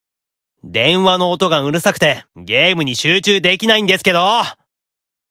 Notification Audio Files
Leviathan_Call_Notification_(NB)_Voice.ogg